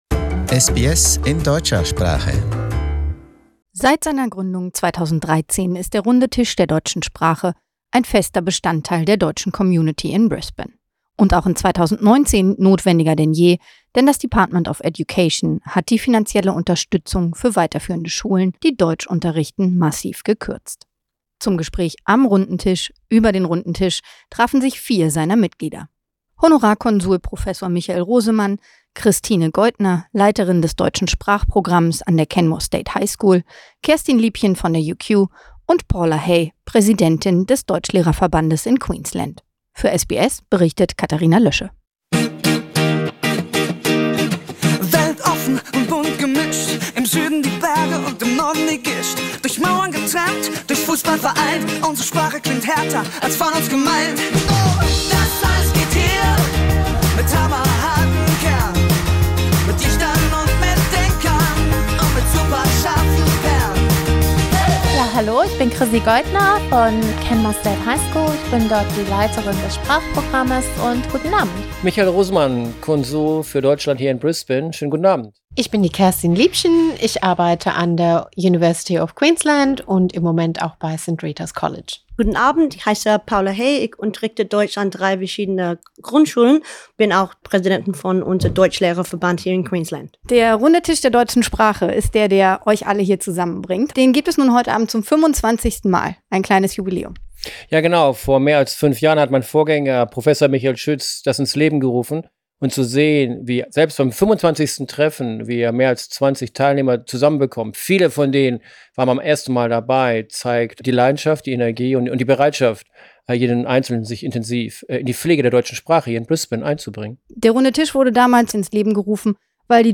invites members of the German community to a round-table discussion about the state of the German Language in Queensland.